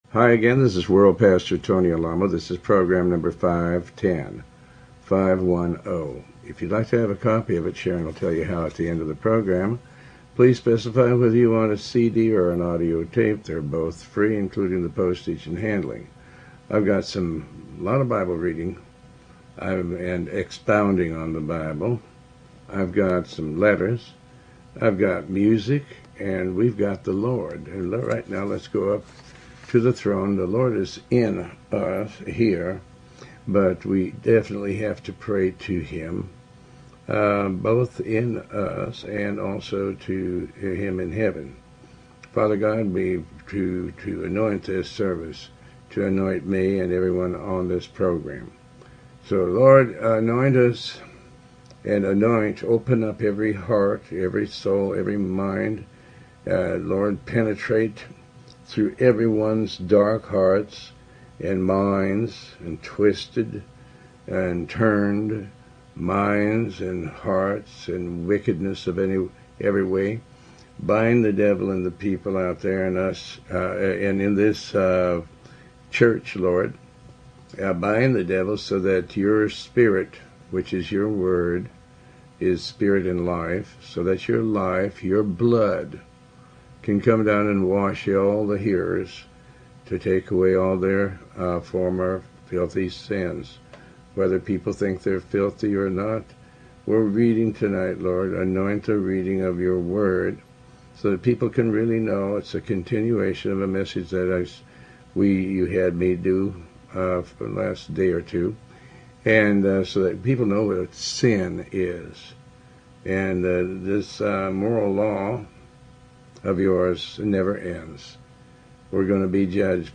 Talk Show Episode, Audio Podcast, Tony Alamo and Program510 on , show guests , about Tony Alamo with Tony Alamo World Wide Ministries, categorized as Health & Lifestyle,History,Love & Relationships,Philosophy,Psychology,Christianity,Inspirational,Motivational,Society and Culture